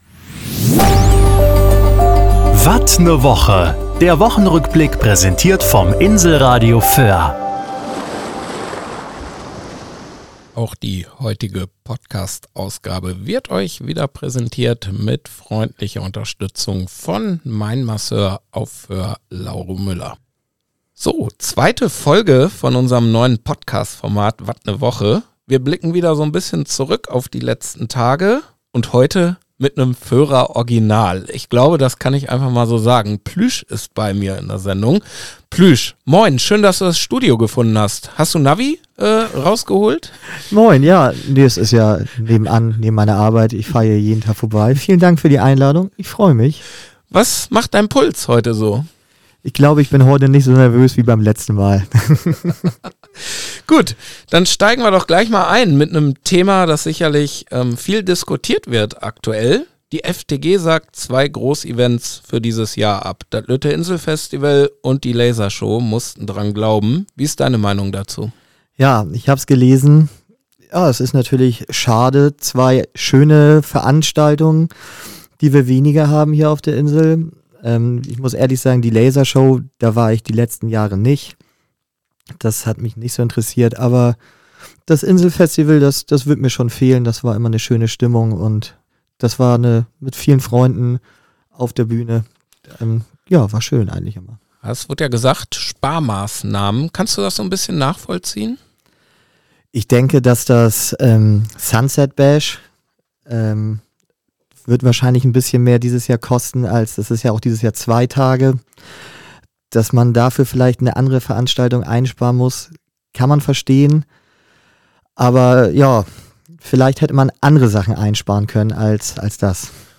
Immer mit einem spannenden, wechselnden Gast, der seine Perspektive einbringt.